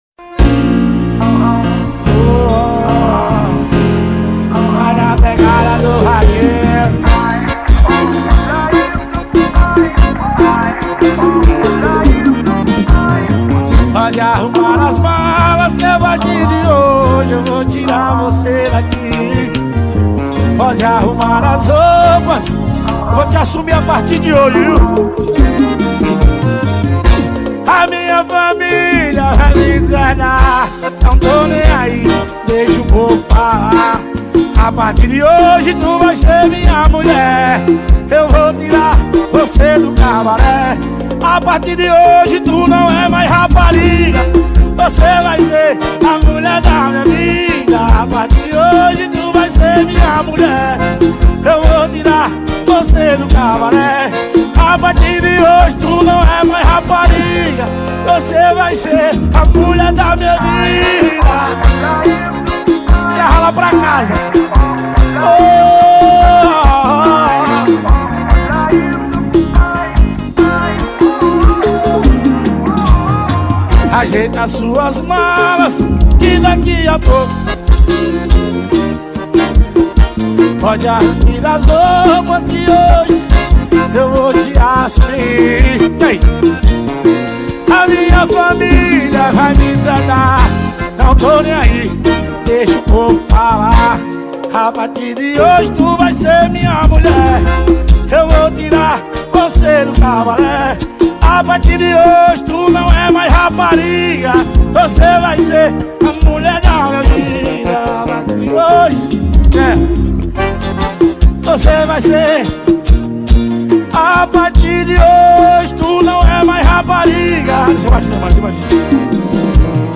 2024-11-10 00:26:38 Gênero: Forró Views